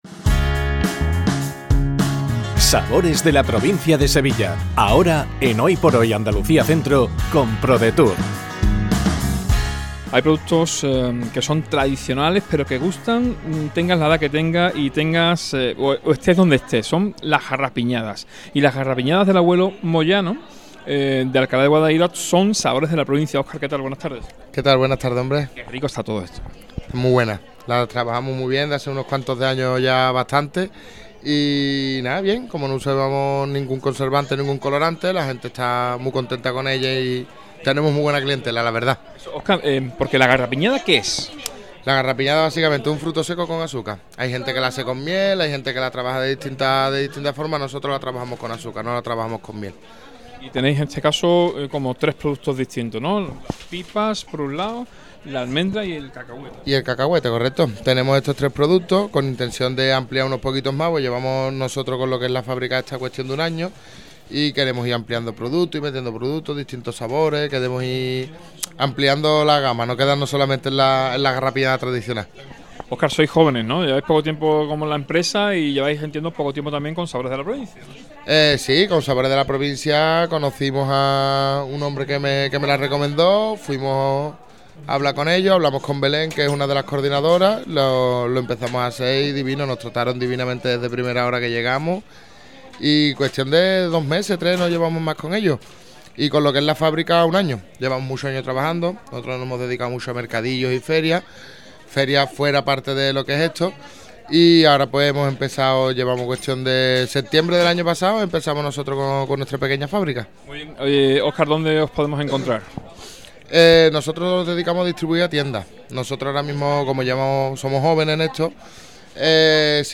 ENTREVISTA | GARRAPIÑADAS EL ABUELO MOYANO